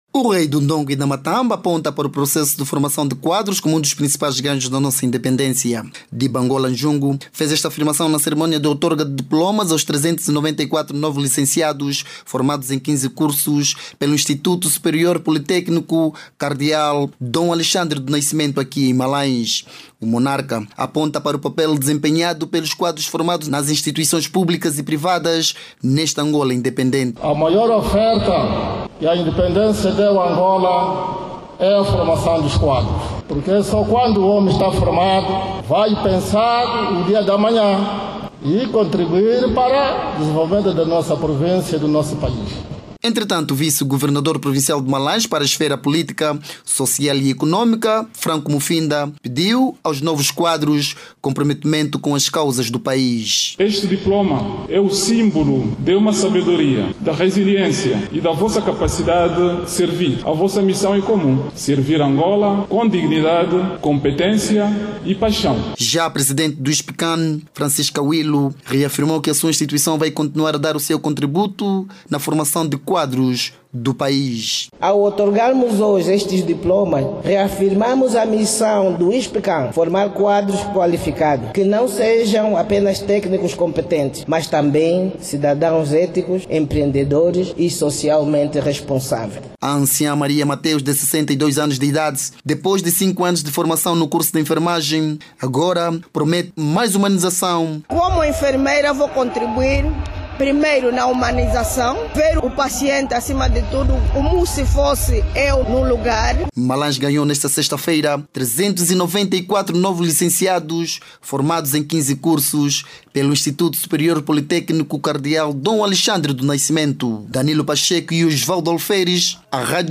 O Instituto Superior Politécnico Cardeal Dom Alexandre do Nascimento, em Malanje, colocou no mercado 394 novos licenciados. Os novos quadros que chegam para reforçar o mercado nacional, foram formados em 15 cursos ministrados pelo instituto.  Ouça no áudio abaixo toda informação com a reportagem